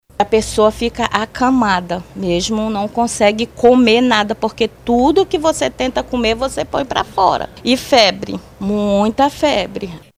A dona de casa também descreve os sintomas da doença.